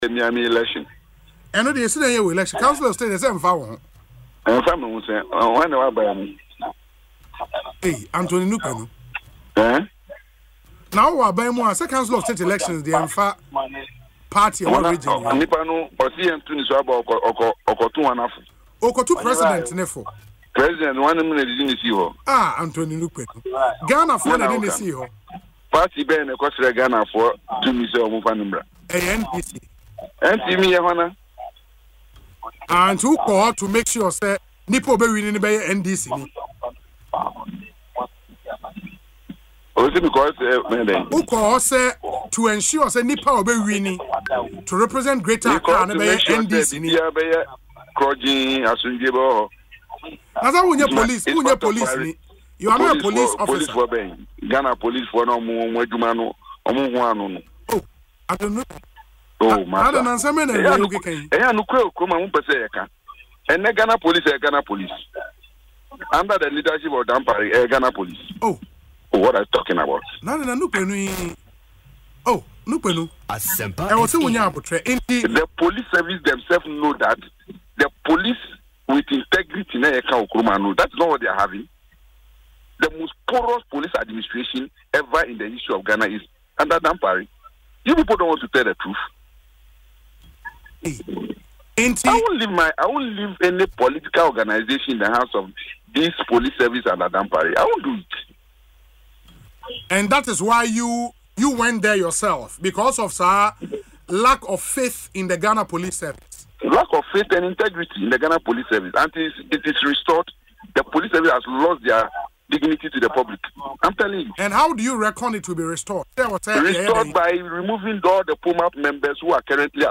Speaking in an interview on Asempa FM’s Ekosii Sen